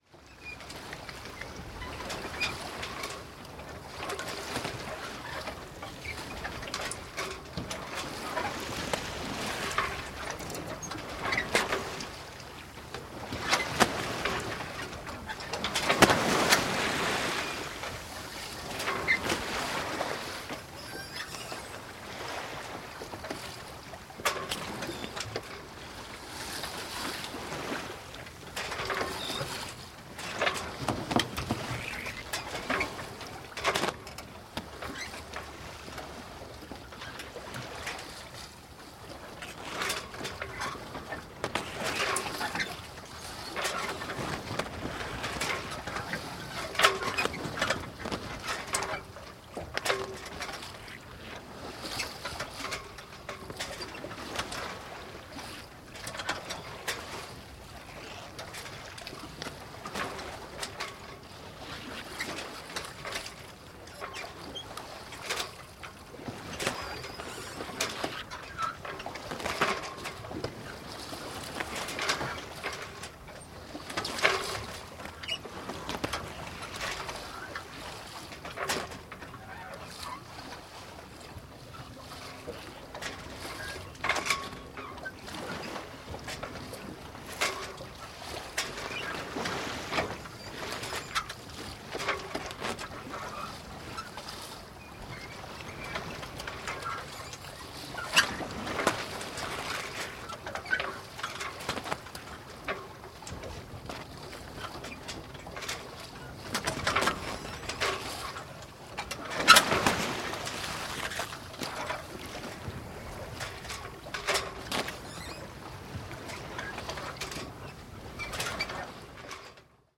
Погрузитесь в атмосферу морских путешествий с коллекцией звуков паруса: шелест натянутой ткани, ритмичный стук волн о борт, крики чаек.
Шум парусника в дальних водах